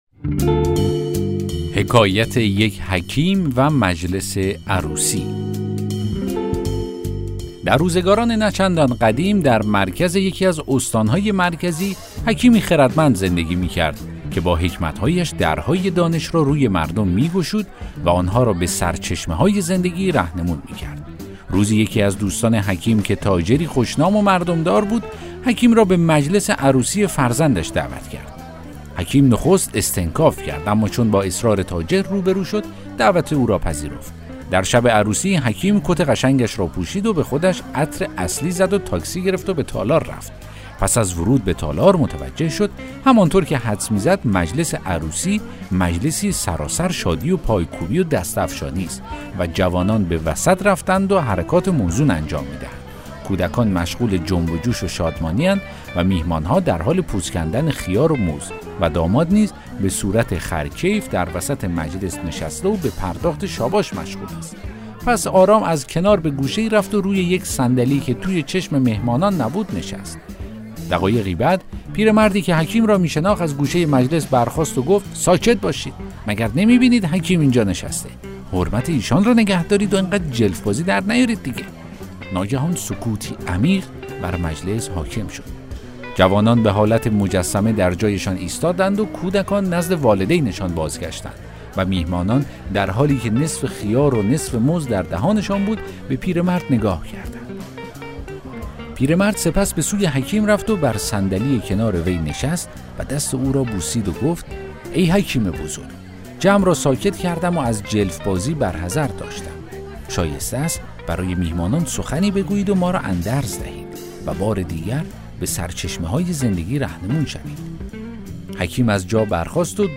داستان صوتی: حکایت یک حکیم و مجلس عروسی